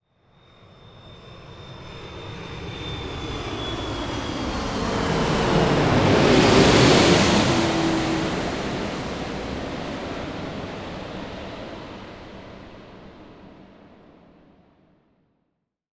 دانلود آهنگ سوت هواپیما 4 از افکت صوتی حمل و نقل
دانلود صدای سوت هواپیما 4 از ساعد نیوز با لینک مستقیم و کیفیت بالا
جلوه های صوتی